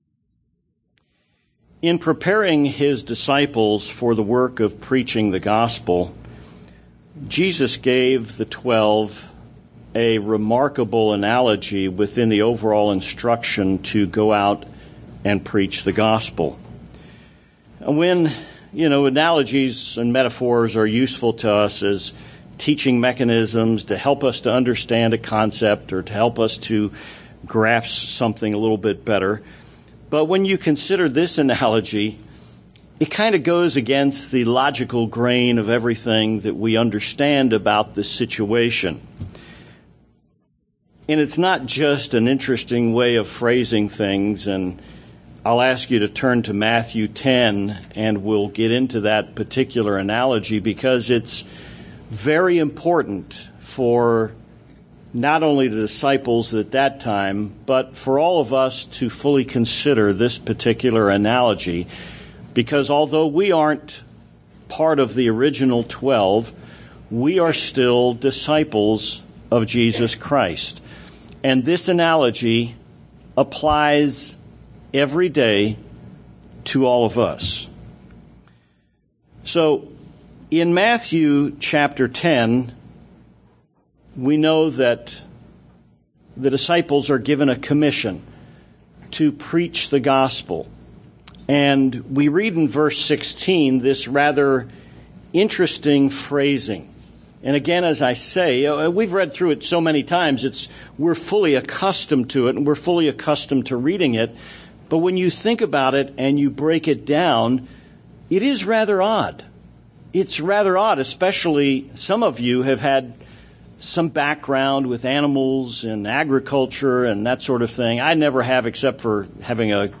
Sermons
Given in El Paso, TX Tucson, AZ